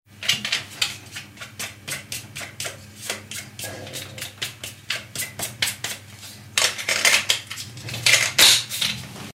Limpiar una máquina de afeitar eléctrica
afeitadora
Sonidos: Acciones humanas
Sonidos: Hogar